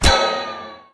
rifle_hit_altmetal2.wav